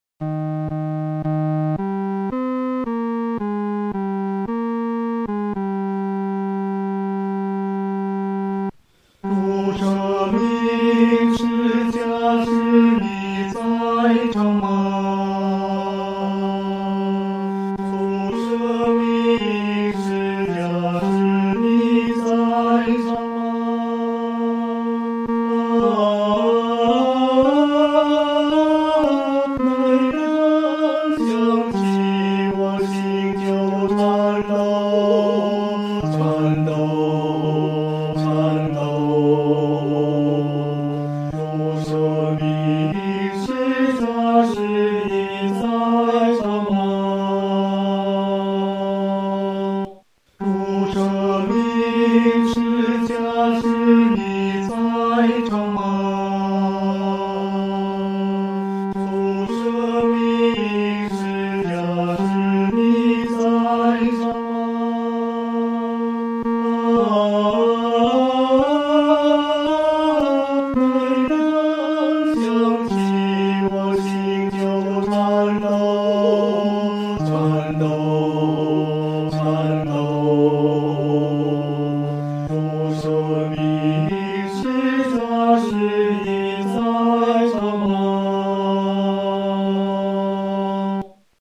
合唱
本首圣诗由网上圣诗班 (呼市）录制